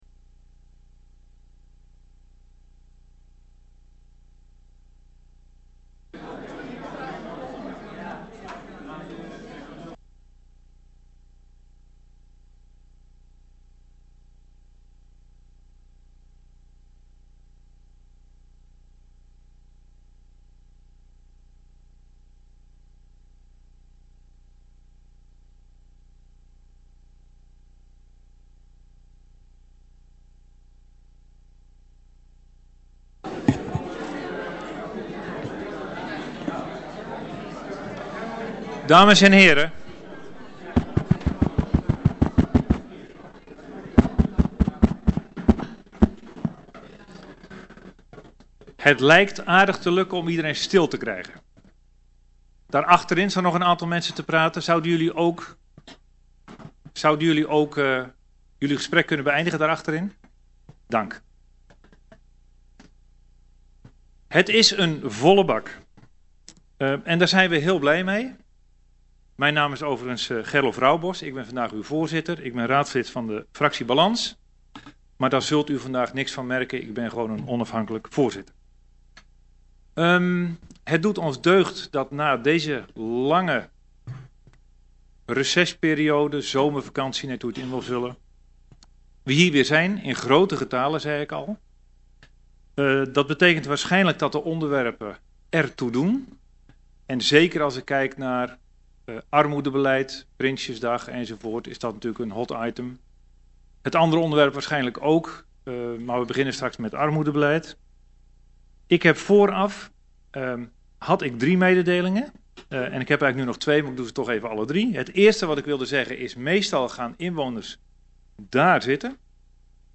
Locatie Raadzaal Boxtel Voorzitter Gerlof Roubos Toelichting .